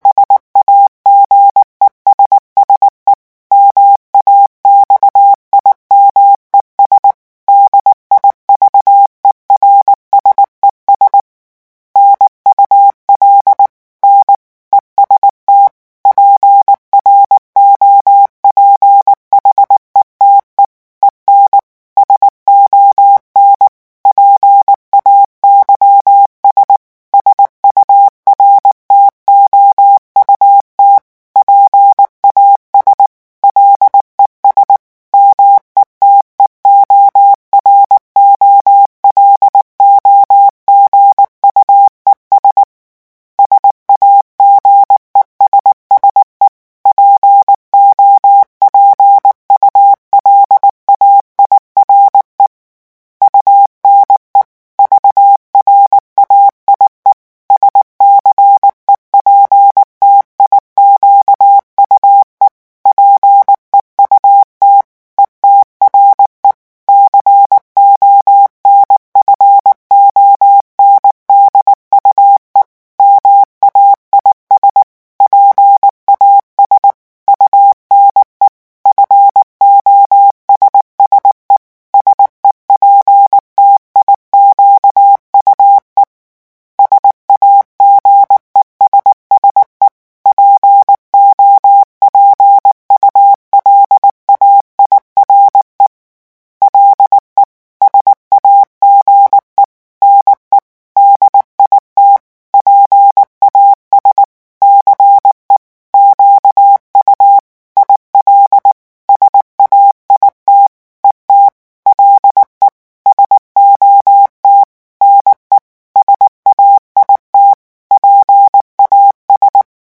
CODE MORSE - REVISION 8
18 mots minutes :
revision_francais_18_mots_minute.mp3